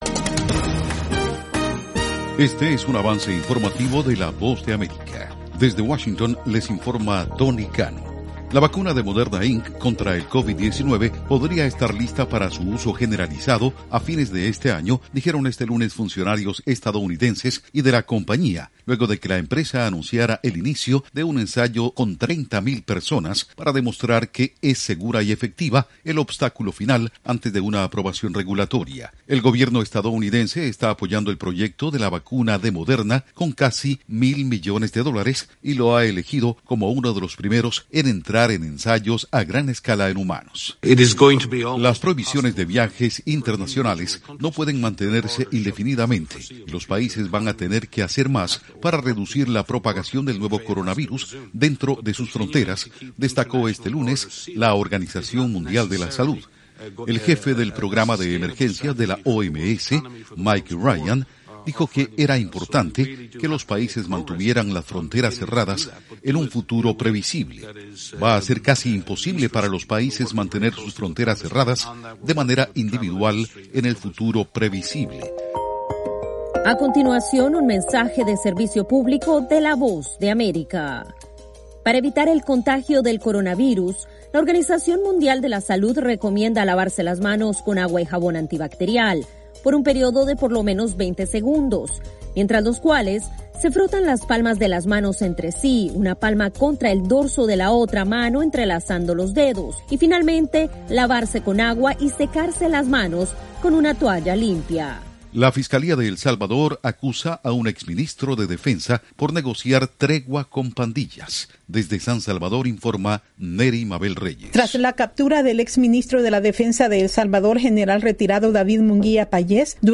Algunas de las noticias de la Voz de América en este avance informativo: